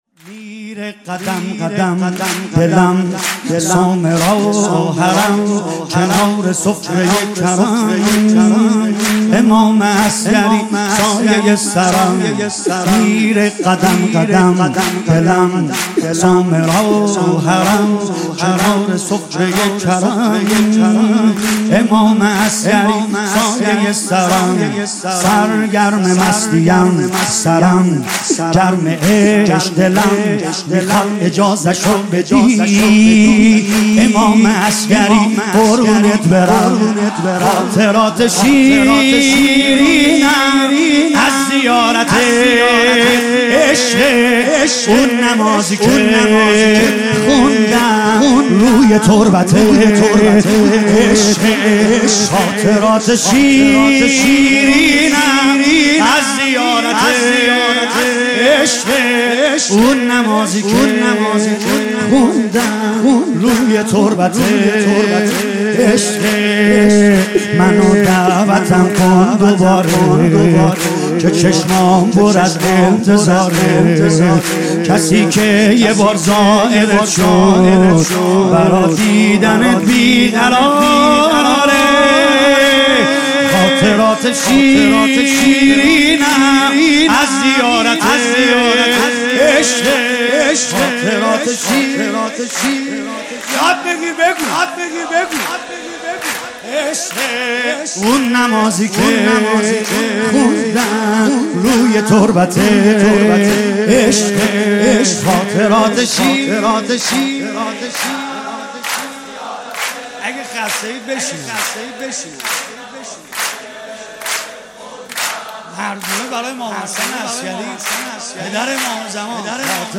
مولودی شورانگیز